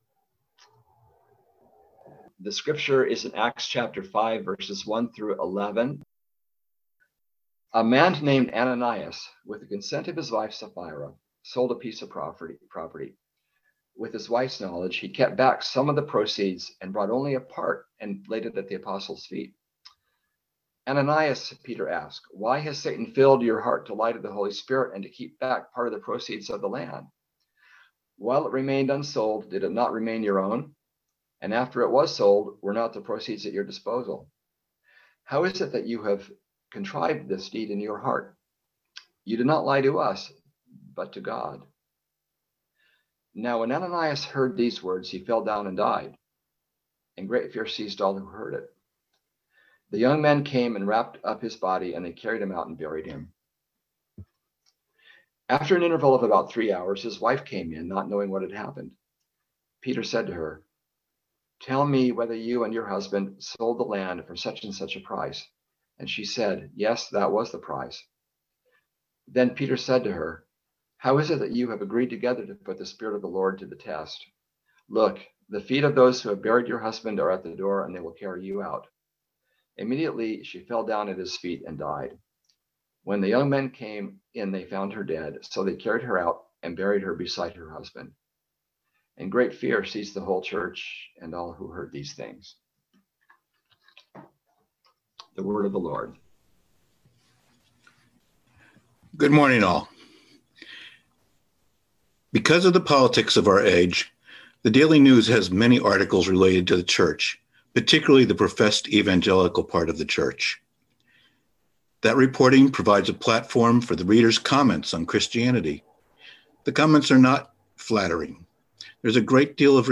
Listen to the most recent message from Sunday worship at Berkeley Friends Church, “Faith and Stewardship.”